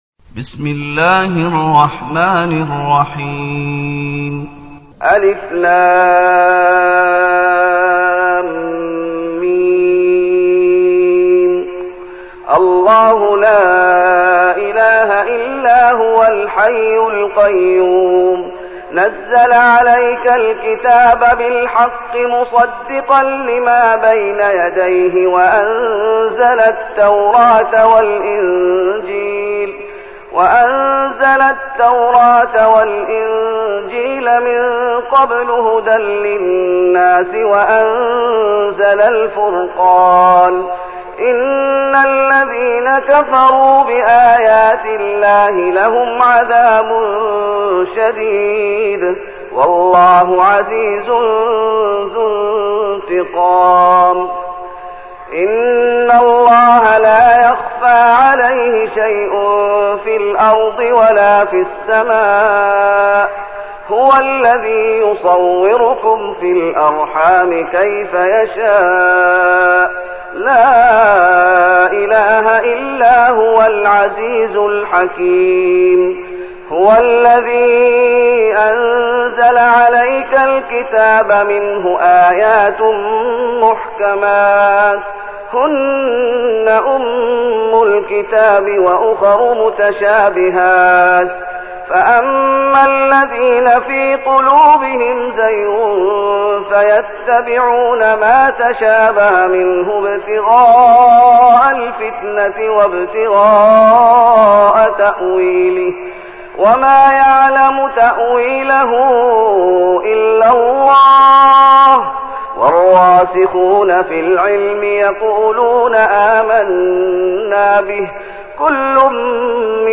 المكان: المسجد النبوي الشيخ: فضيلة الشيخ محمد أيوب فضيلة الشيخ محمد أيوب آل عمران